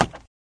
woodwood3.ogg